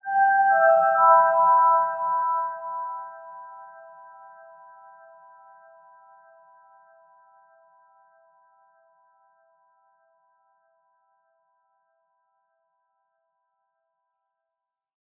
Звук отблеска, луча, просвета (5)
Библиотека Звуков - Звуки и звуковые эффекты - Видео и кино эффекты